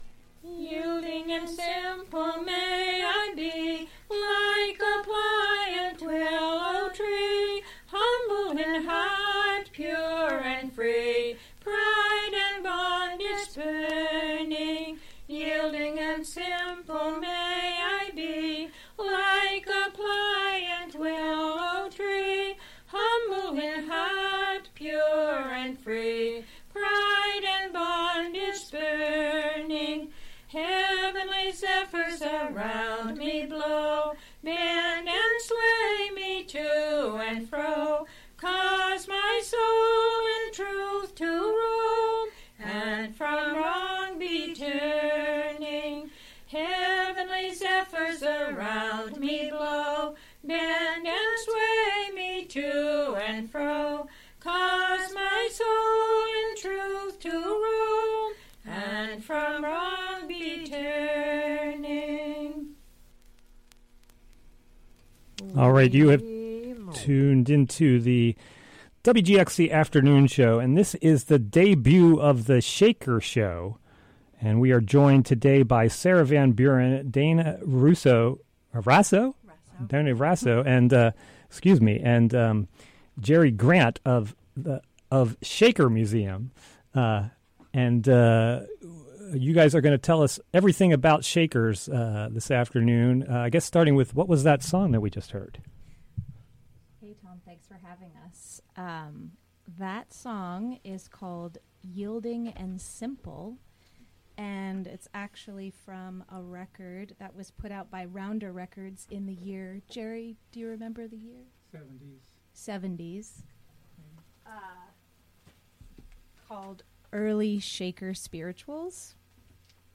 live from the WGXC Hudson studio. The inaugural "Shaker Show" will guide listeners through the history of The Shakers: who they are, where they come from, and where they are now. This hour will cover how they are connected with the legacy of agrarian and communal living here in the Hudson Valley, and also how are they connected to larger social movements of the last two centuries.